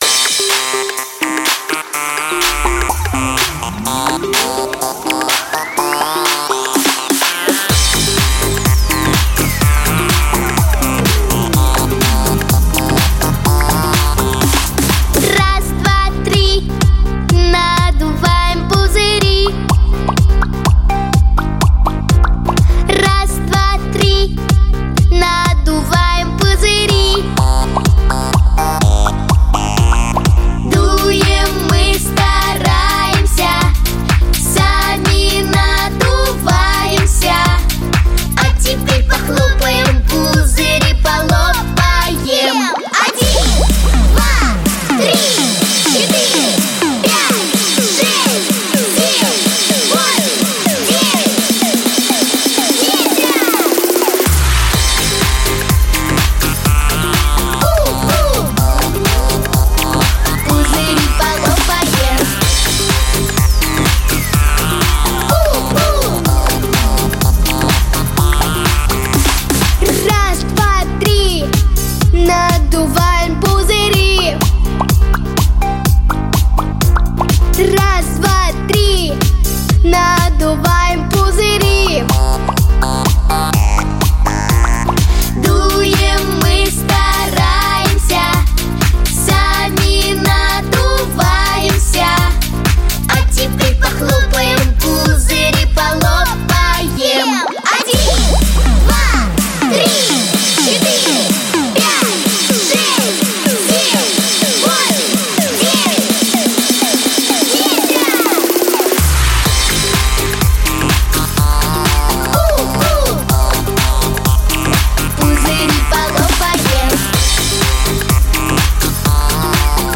Песни из мультфильмов
Современные, новые, популярные песни для детей 👶👧